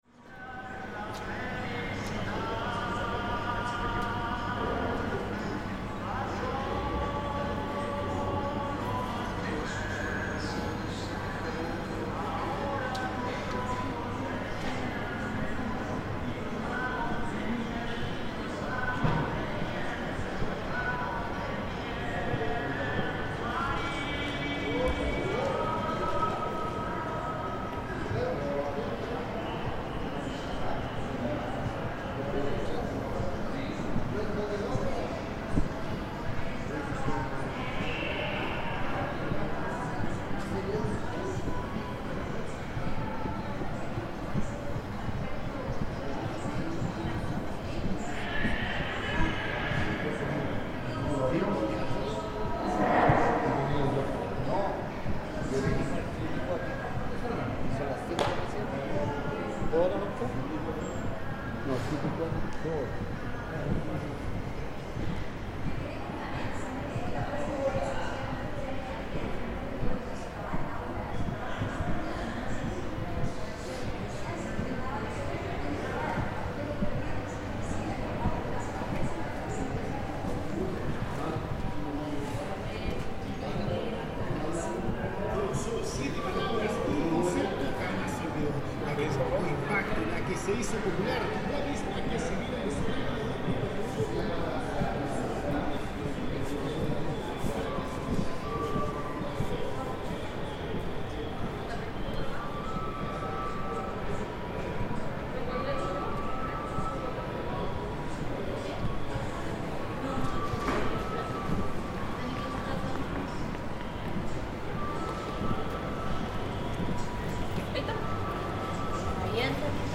A long layover in one of the world's most southerly airports, at the tip of Chilean Patagonia, as we waited for a flight back to Santiago. There's very little to see or do in this tiny airport, as you might expect, but you can hear the sounds of traditional Chilean music being piped in, some chatter in Spanish, and those typical sounds of a transient space holding mostly people who are on their way to - or from - an incredible trip to one of the most beautiful parts of the world.